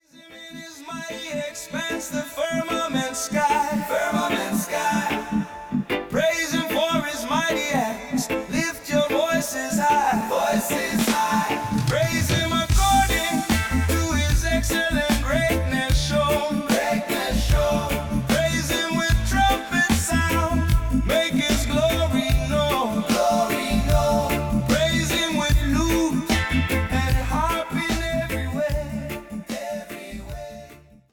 An explosive anthem of worship with full reggae energy
Artist/Performer: (singer/band) – AI